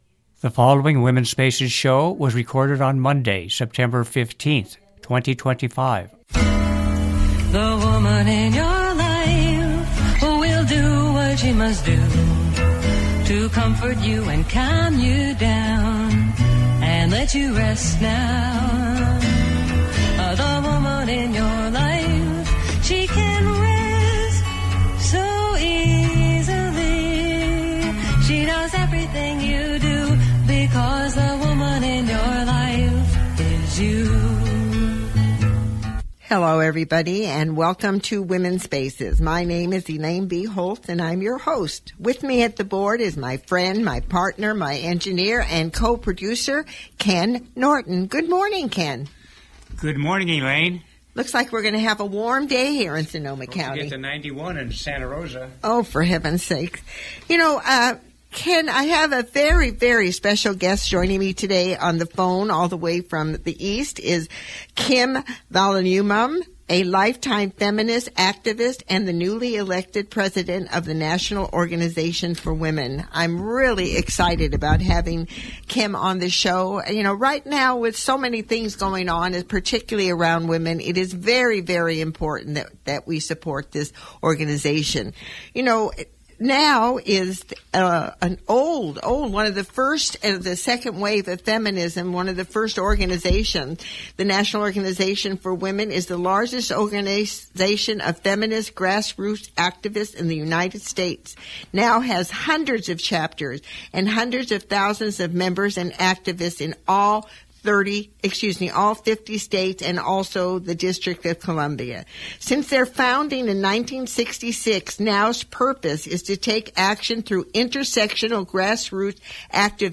live radio interview